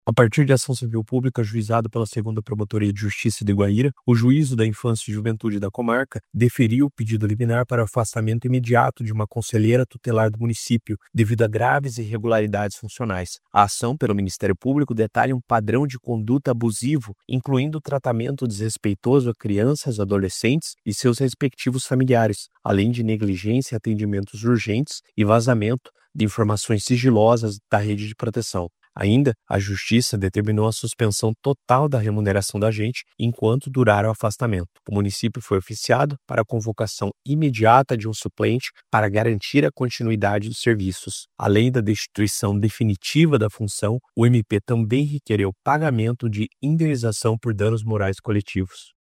Ouça o que diz o promotor de Justiça Renan Goes de Lima.